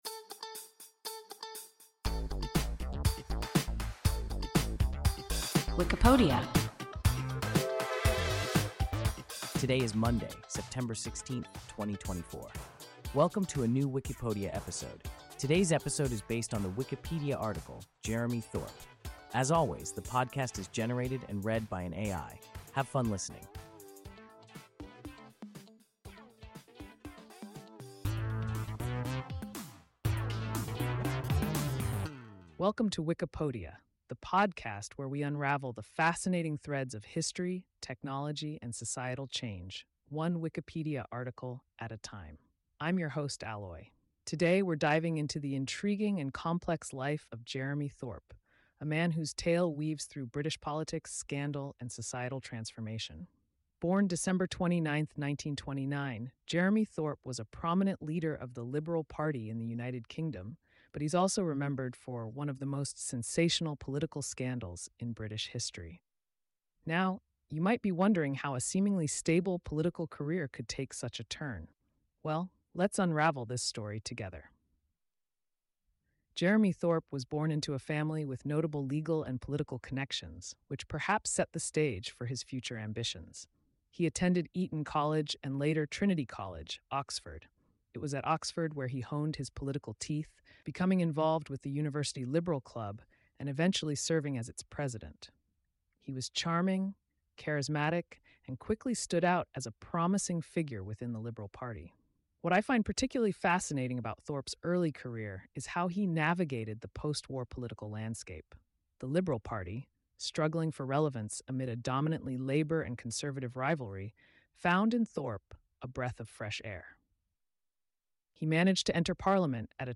Jeremy Thorpe – WIKIPODIA – ein KI Podcast